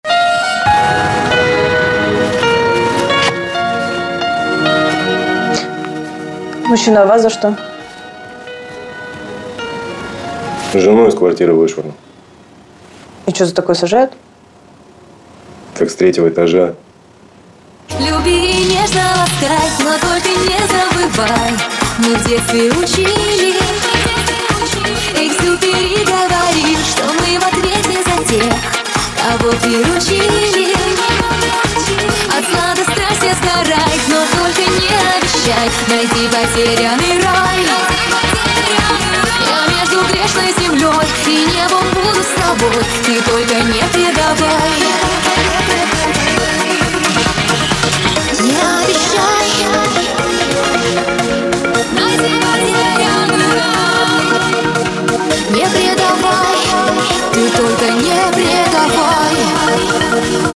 • Качество: 320, Stereo
поп
громкие
женский голос
танцевальные